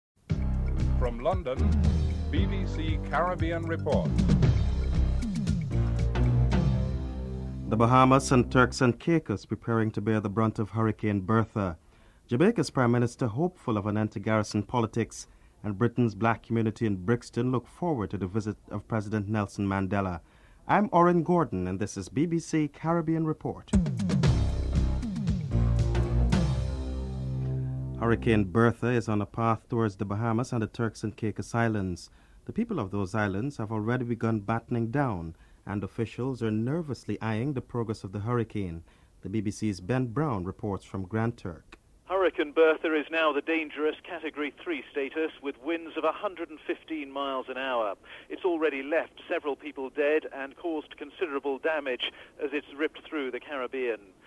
The British Broadcasting Corporation
1. Headlines (00:00-00:27)